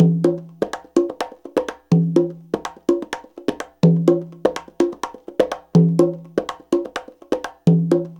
CONGA BEAT21.wav